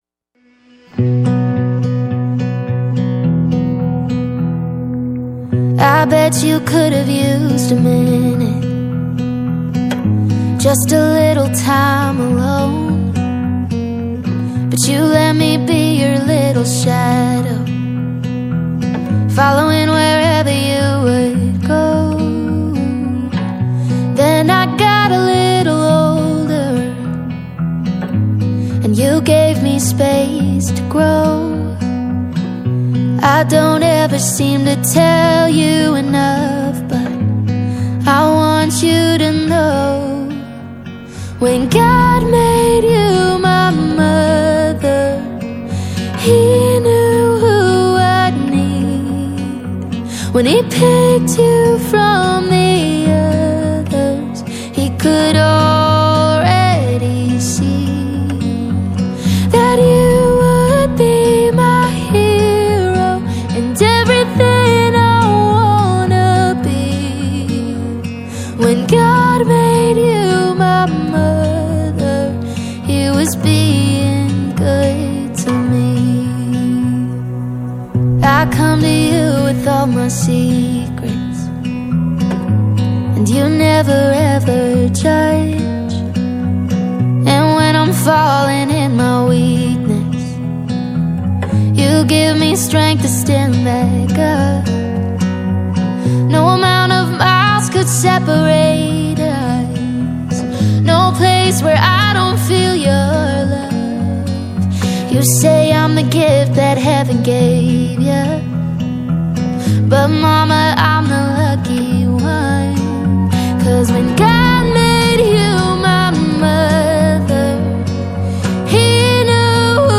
International Gospel Songs